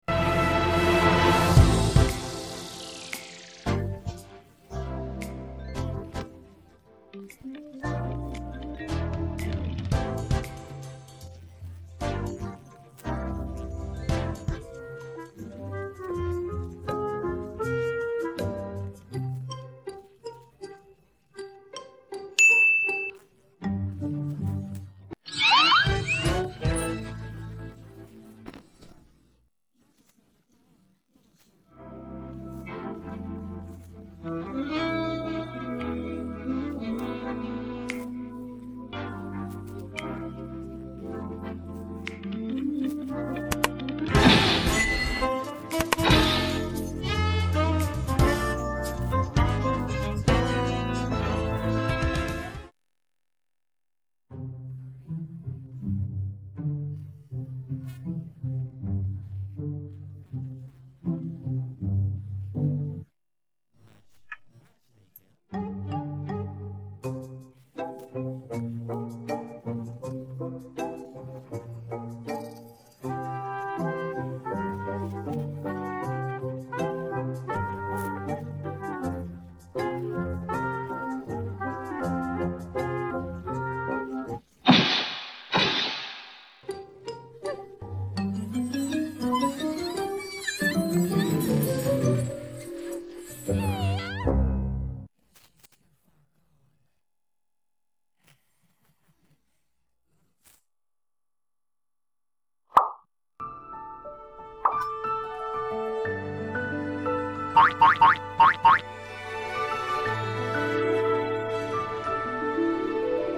Música de fondo